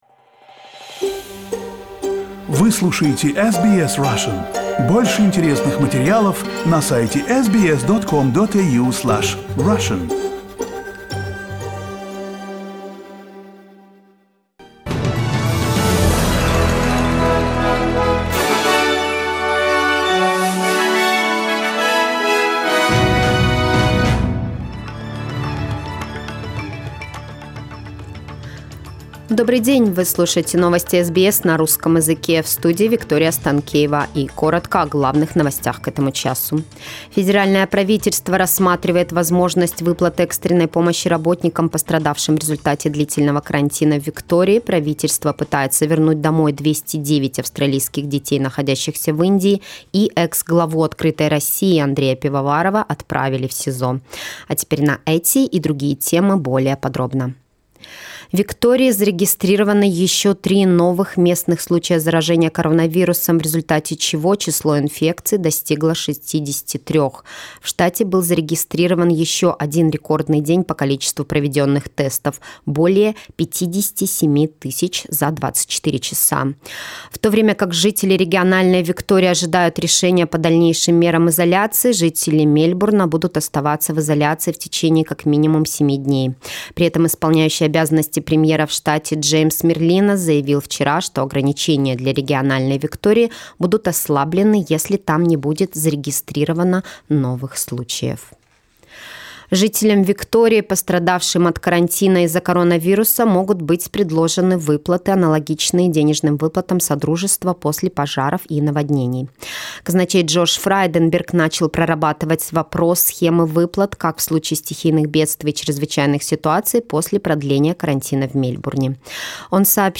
SBS news in Russian - 3.06